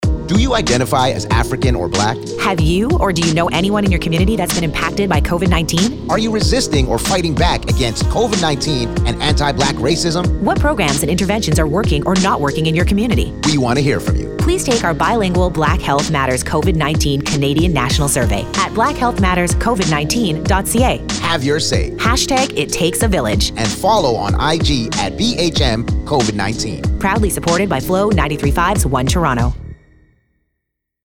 Flow 93.5 – On-Air 30 sec. PSA